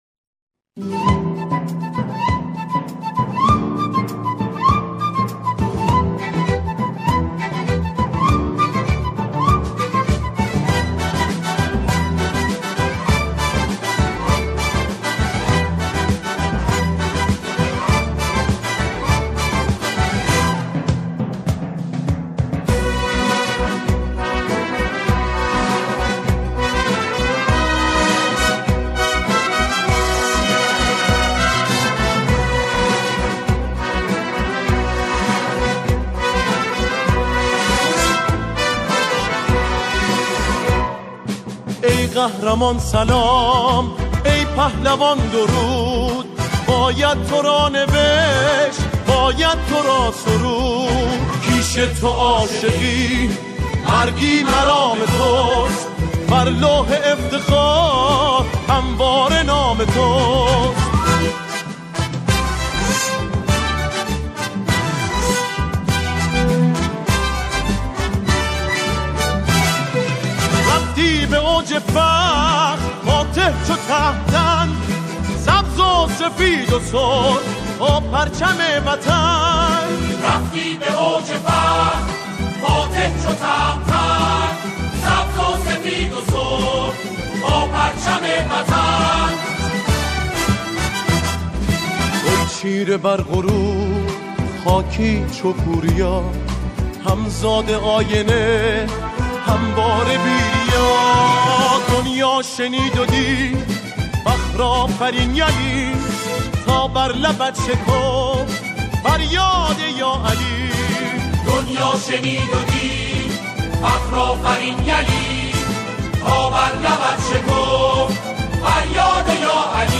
سرودهای ورزشی
گروهی از جمعخوانان اجرا می‌کنند